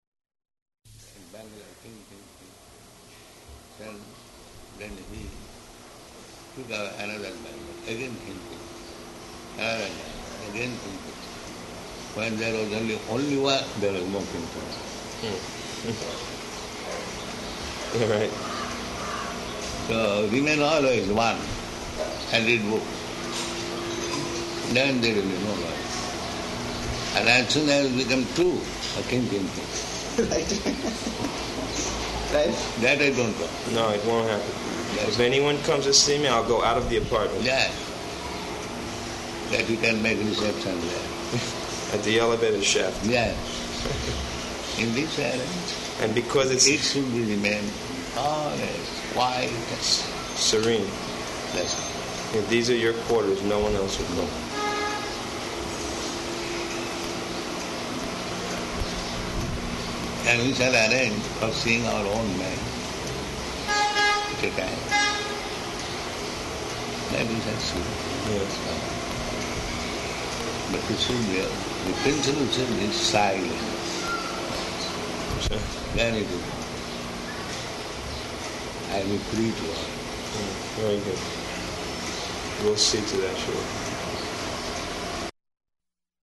Room Conversation [partially recorded]
Location: Bombay